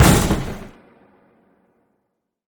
tableSlamNoBox.ogg